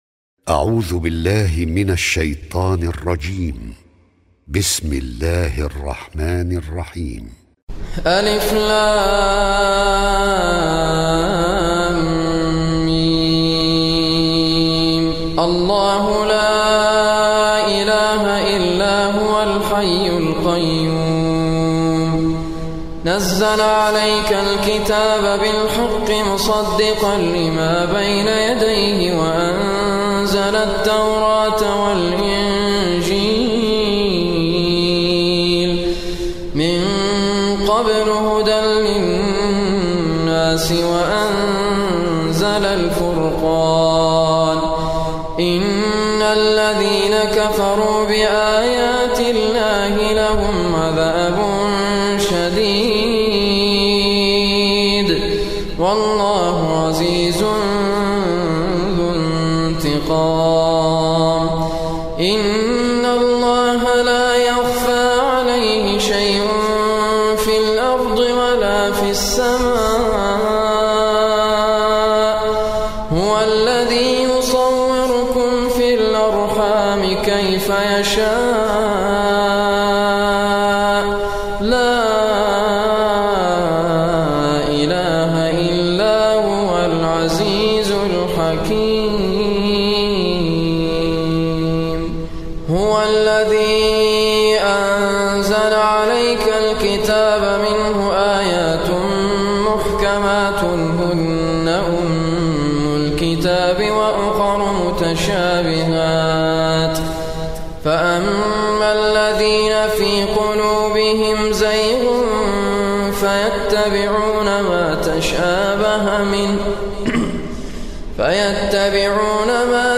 Surah Raad is third surah of Holy Quran. Listen or play online mp3 tilawat / recitation in arabic in the beautiful voice of Sheikh Raad Al Kurdi.